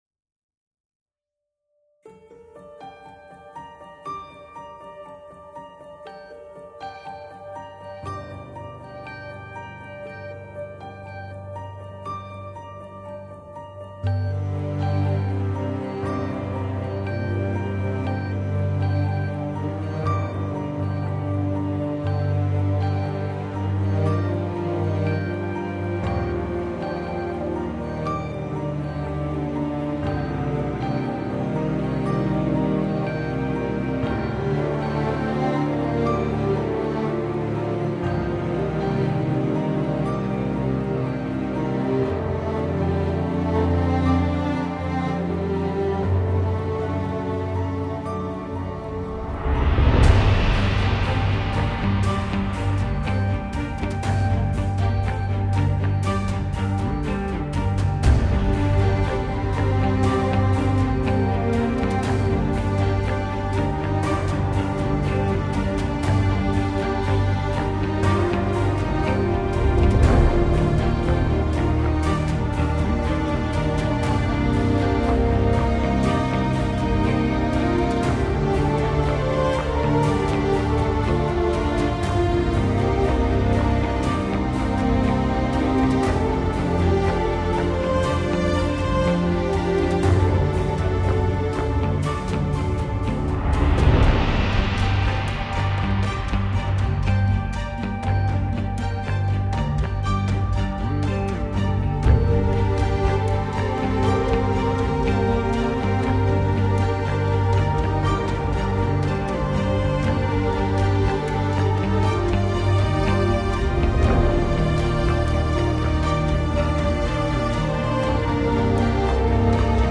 different string meldody